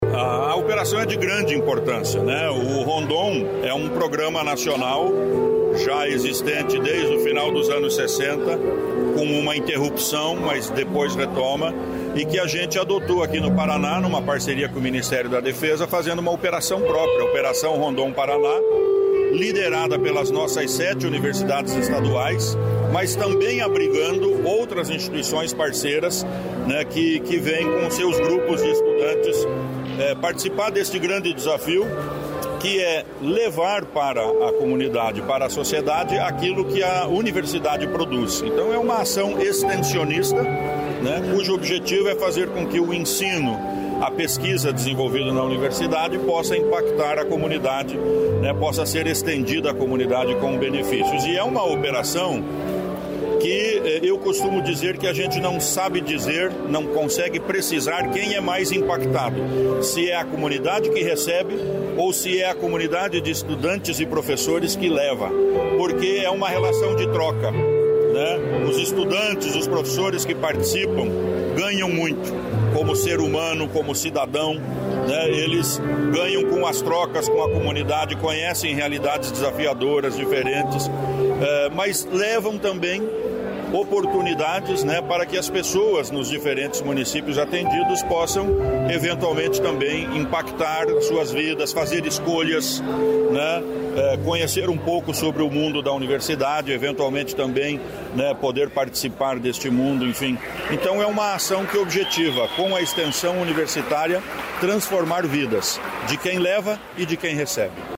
Sonora do secretário da Ciência, Tecnologia e Ensino Superior, Aldo Nelson Bona, sobre ações de inclusão e sustentabilidade no Norte Pioneiro